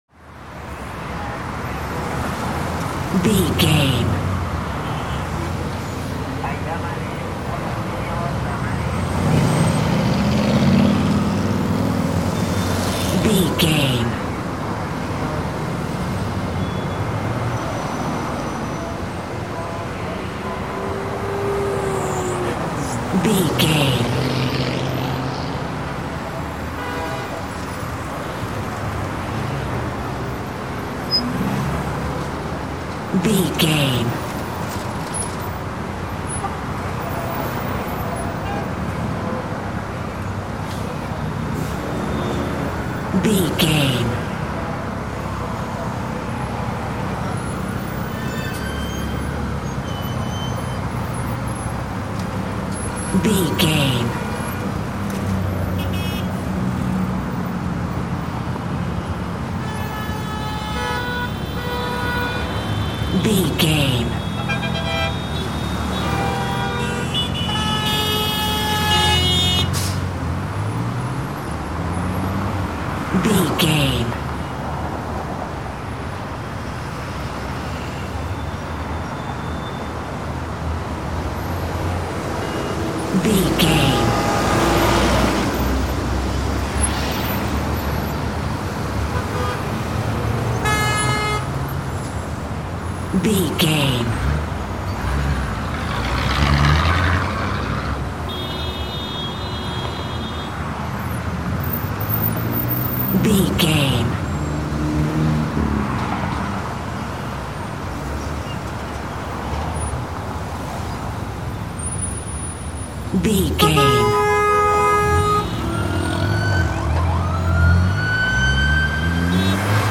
City street heavy traffic
Sound Effects
urban
chaotic
ambience